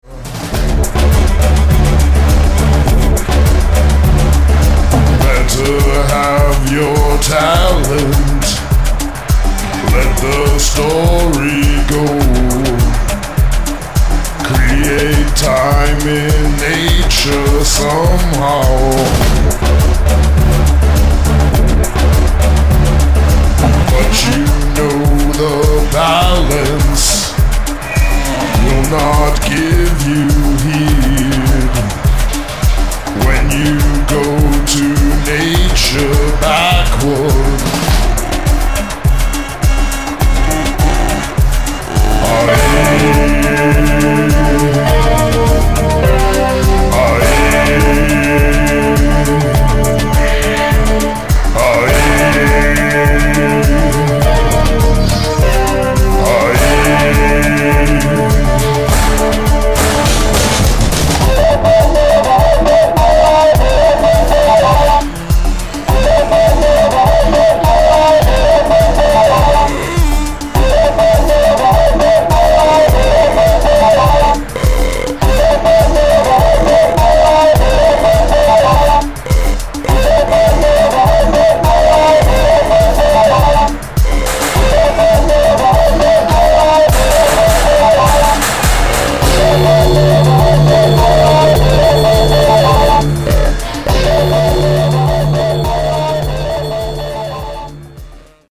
offer an electronic version
Track 3 is an exclusive mix